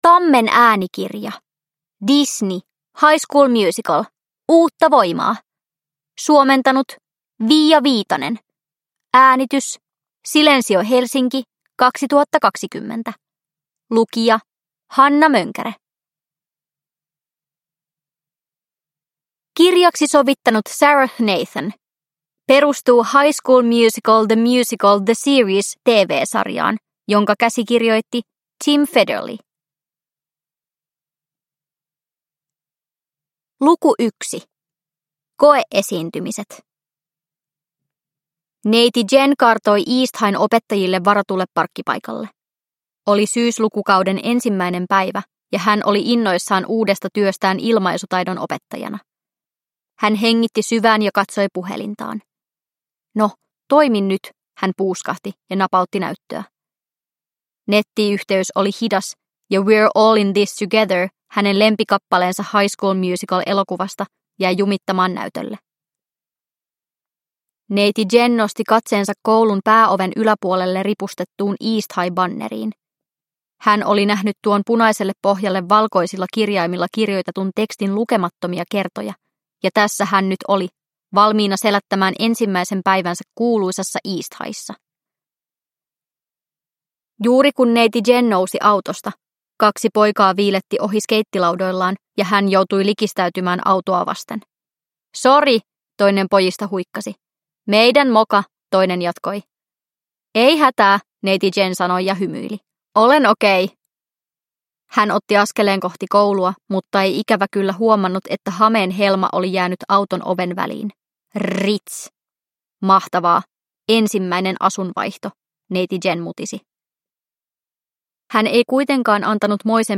High School Musical. Uutta voimaa! – Ljudbok – Laddas ner